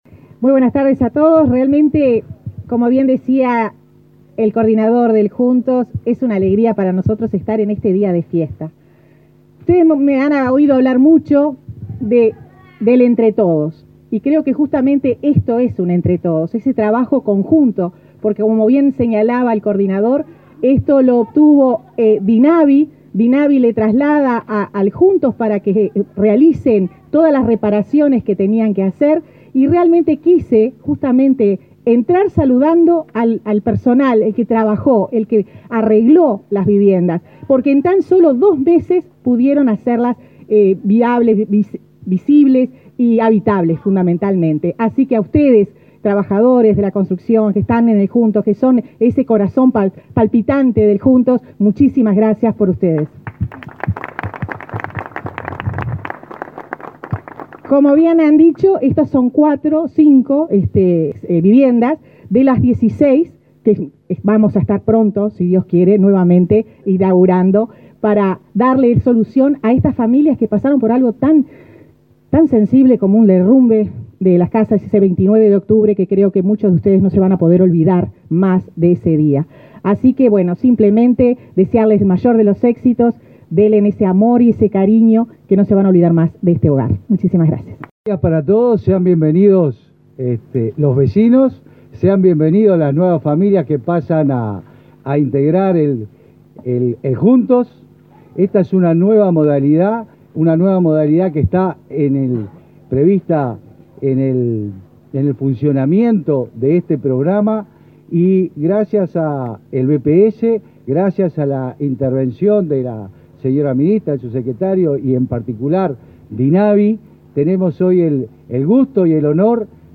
Palabras de autoridades del Ministerio de Vivienda